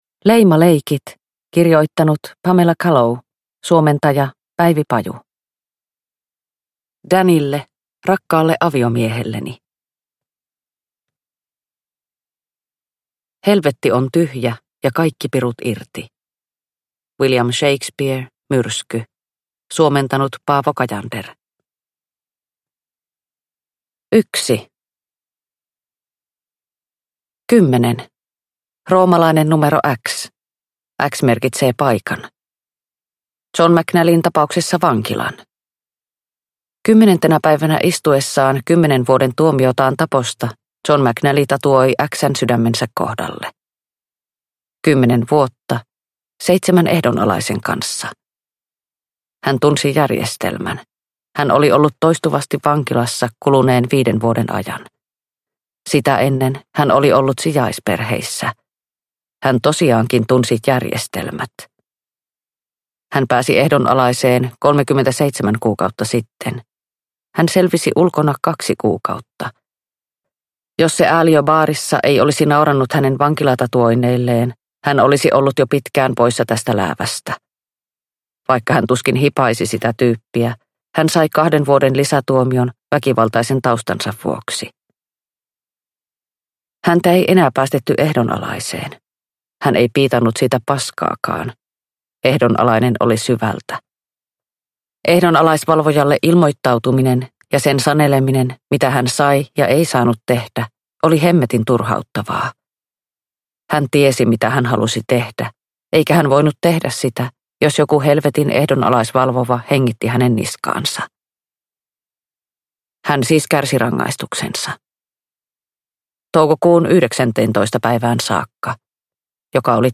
Leimaleikit – Ljudbok – Laddas ner